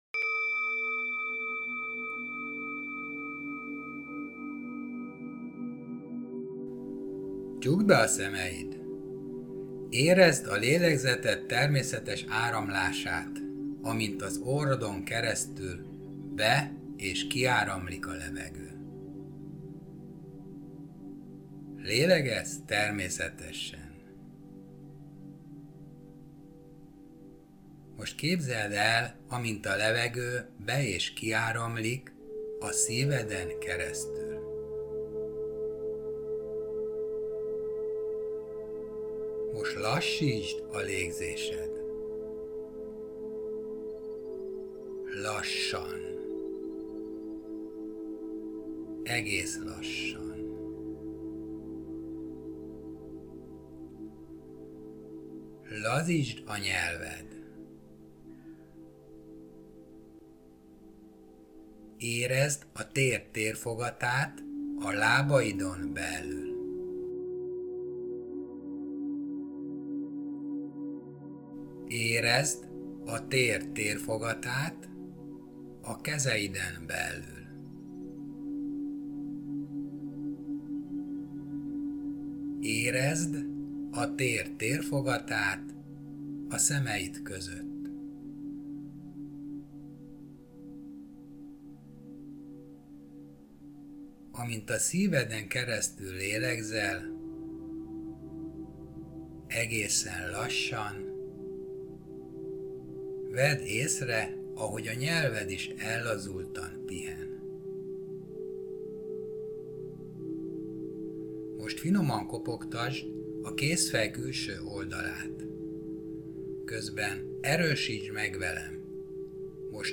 Meditáció: A megelégedettség érzését választom